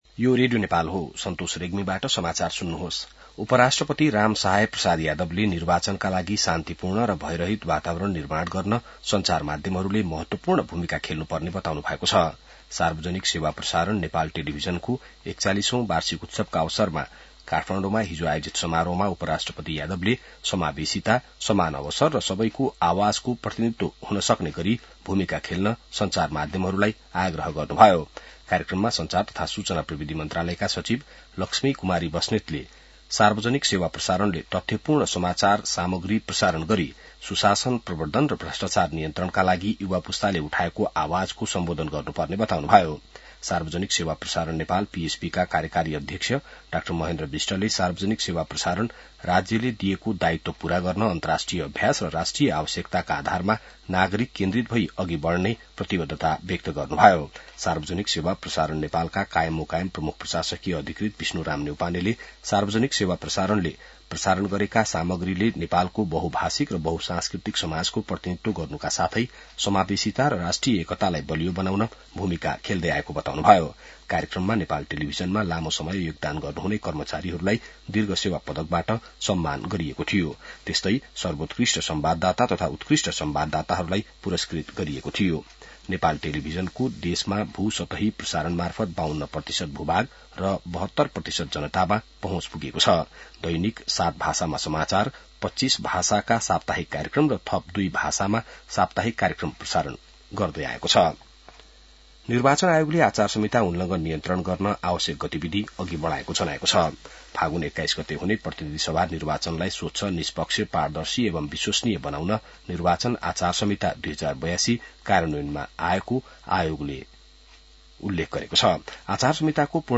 बिहान ६ बजेको नेपाली समाचार : १८ माघ , २०८२